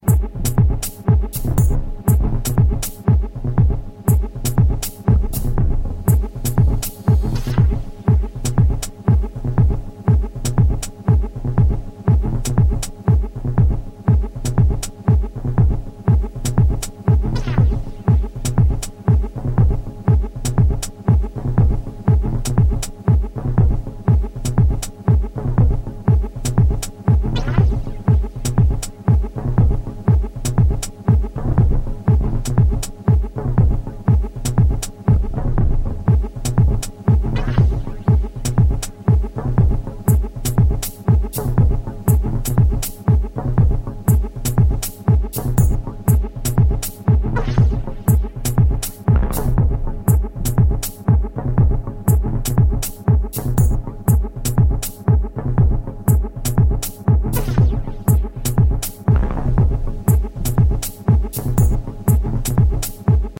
一般的なパターンとは一味も二味もズラしてくるリズム、そしてザラついた質感の妙。